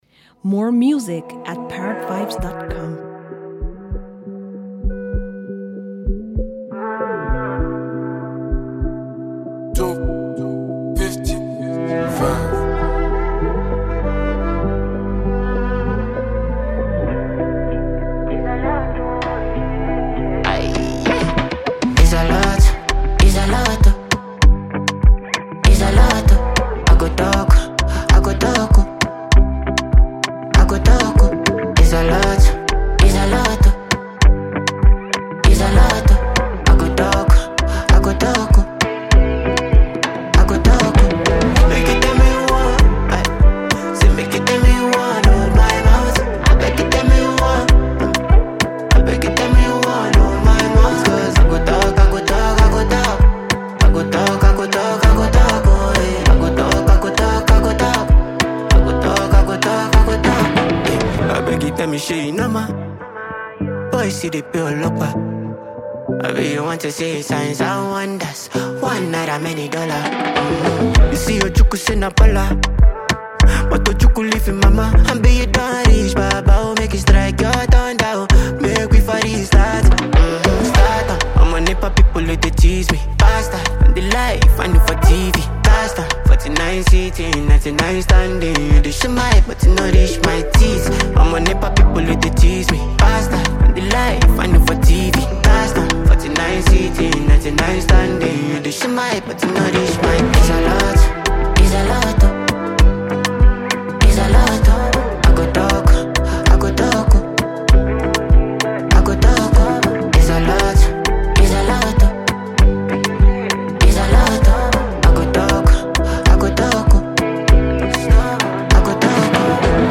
Renowned German music trio